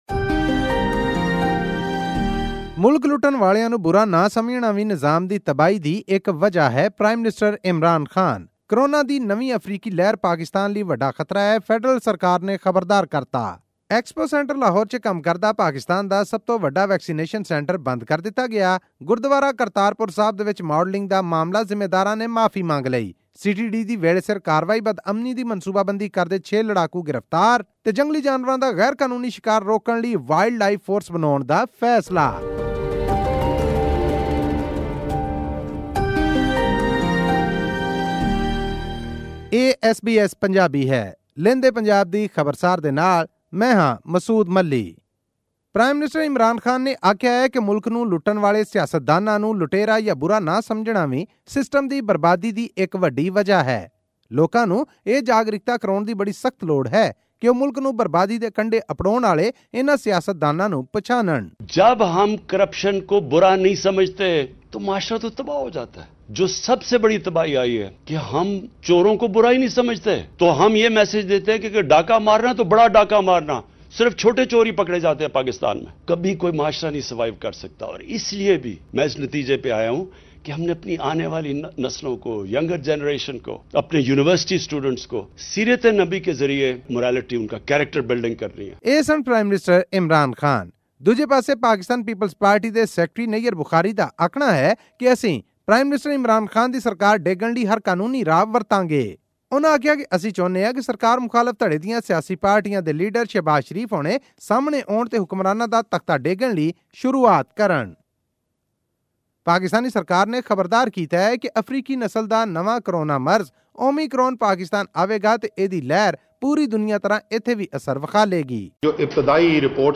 A Pakistani model has sparked controversy with her bare head photoshoot at Gurdwara Darbar Sahib in Kartarpur that has hurt the sentiments of the Sikh community. This and more in our weekly news update from Pakistan.